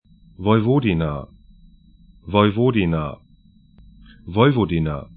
Woiwodina   'vɔyvodina Vojvodina 'vɔyvɔdina sr Gebiet / region 45°16'N, 20°05'E